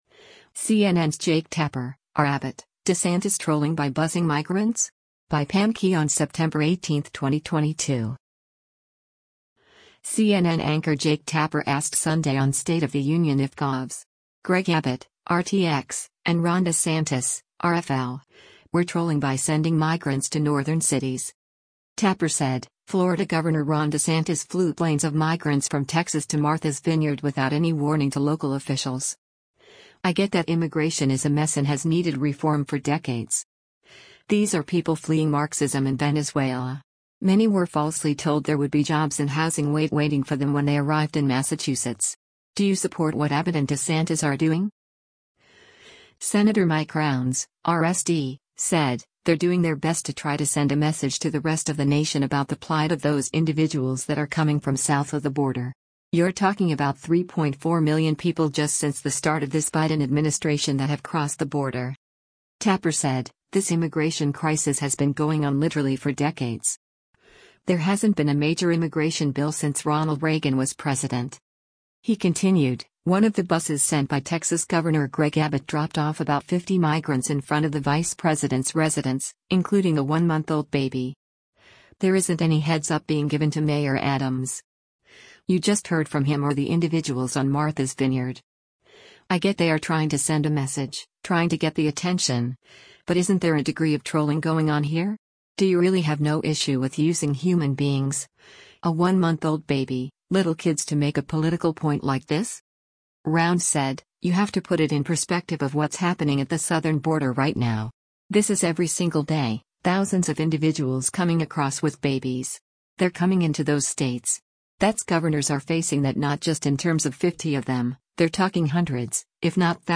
CNN anchor Jake Tapper asked Sunday on “State of the Union” if Govs. Greg Abbott (R-TX) and Ron DeSantis (R-FL) were “trolling” by sending migrants to northern cities.